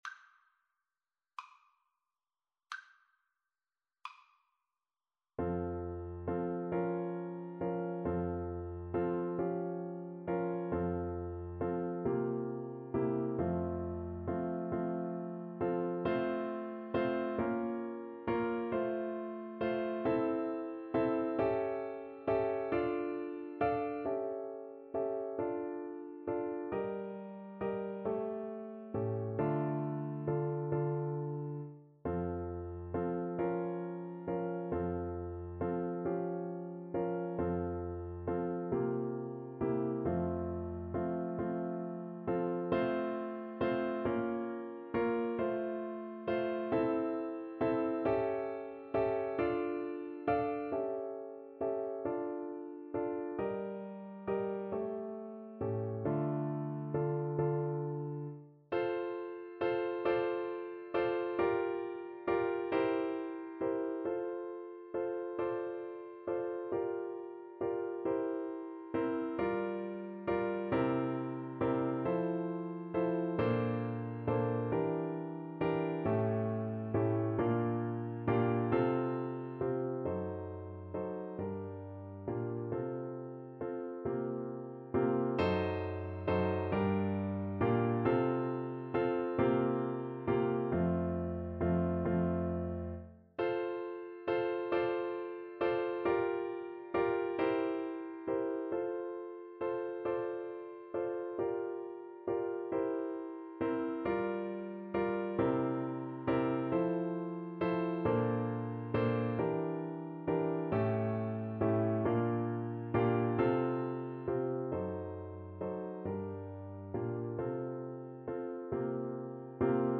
Play (or use space bar on your keyboard) Pause Music Playalong - Piano Accompaniment Playalong Band Accompaniment not yet available reset tempo print settings full screen
Andantino .=c.45 (View more music marked Andantino)
F major (Sounding Pitch) (View more F major Music for Recorder )
6/8 (View more 6/8 Music)
Classical (View more Classical Recorder Music)